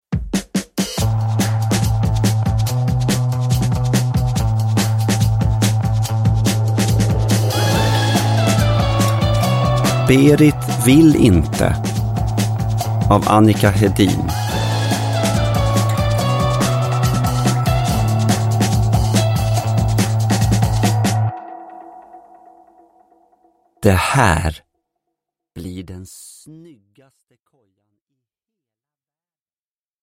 Berit vill inte – Ljudbok – Laddas ner
Uppläsare: Gustaf Hammarsten